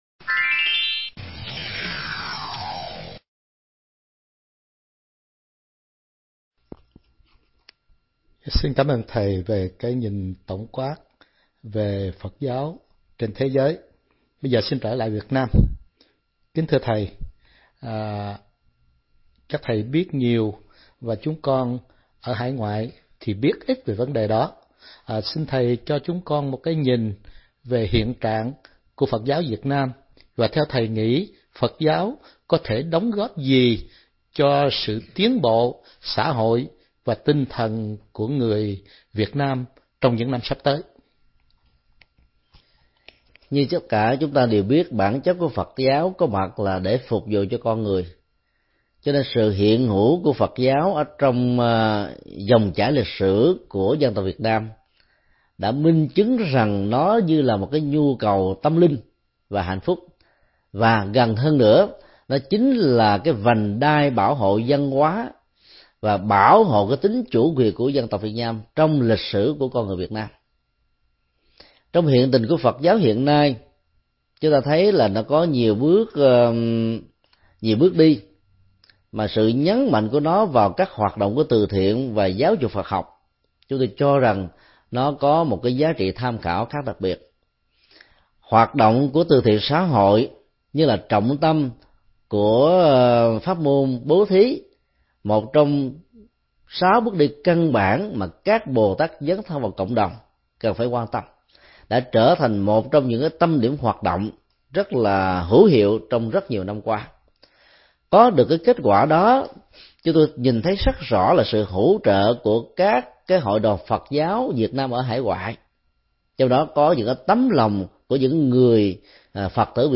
Pháp thoại Tương lai Phật giáo B
Trả lời phỏng vấn chương trình phát thanh Đuốc Tuệ, Santa Ana, ngày 25 tháng 06 năm 2007.